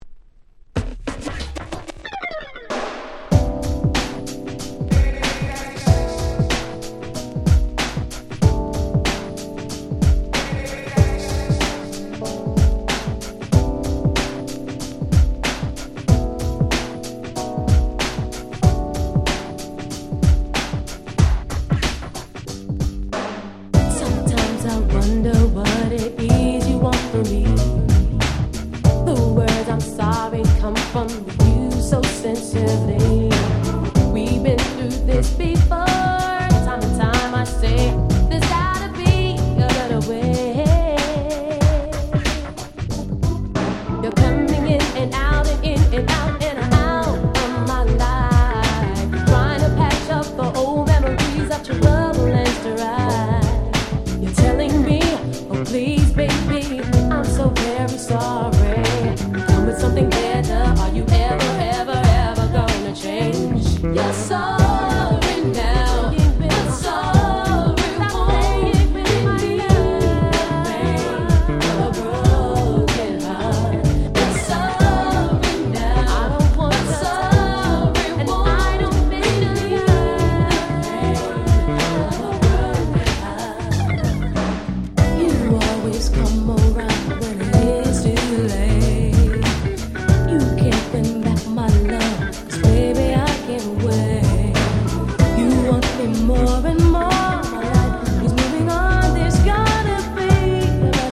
94’ Very Nice R&B !!